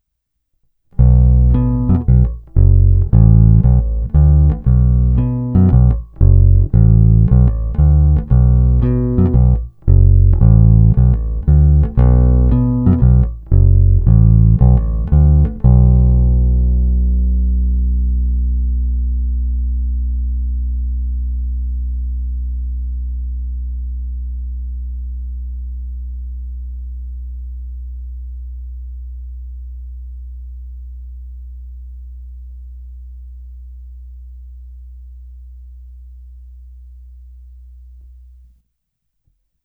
Zvuk je plný, pevný, bohatý středobasový základ doplňuje slušná porce kousavosti.
Není-li uvedeno jinak, následující nahrávky jsou provedeny rovnou do zvukové karty, jen normalizovány, jinak ponechány bez úprav. Tónová clona vždy plně otevřená.
Hra mezi krkem a snímačem